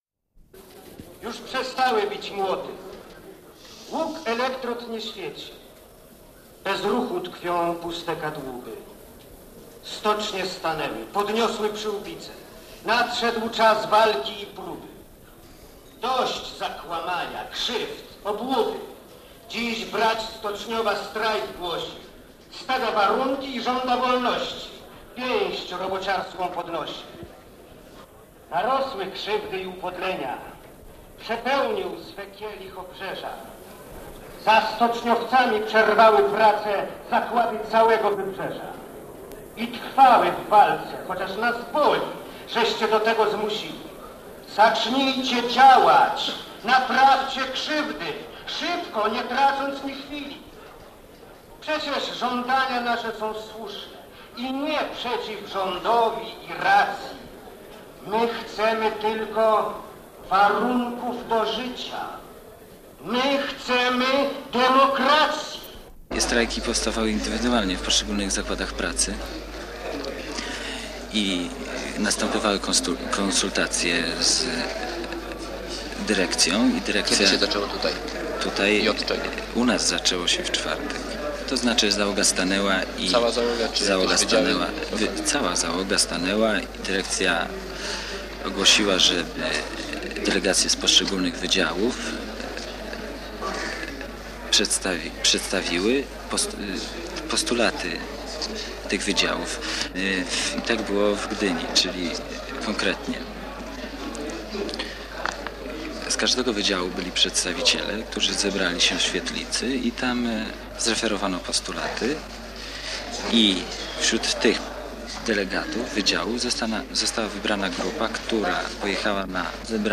Reportaż o wydarzeniach w Stoczni Gdańskiej w sierpniu '80